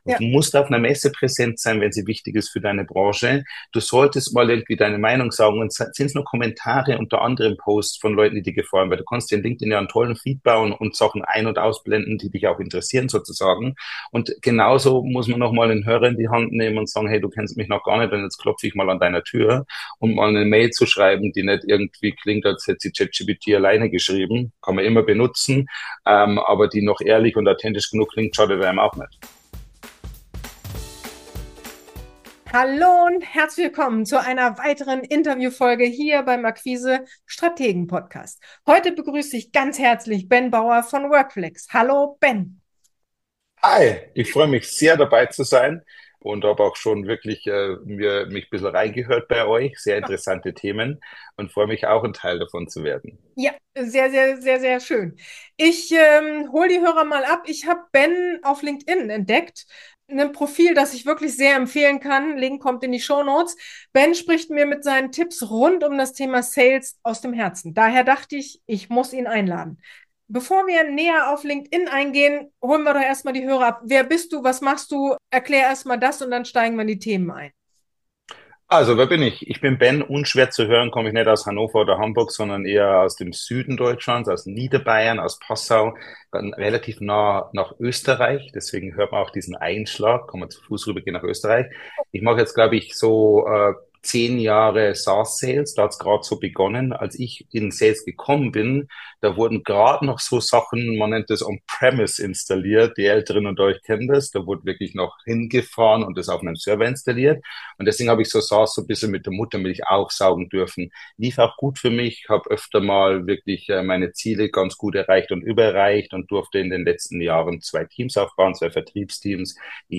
In dieser Episode treffen zwei echte B2B-Experten aufeinander.